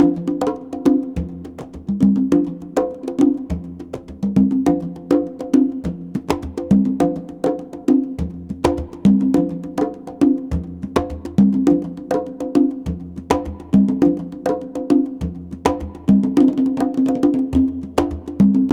CONGBEAT11-R.wav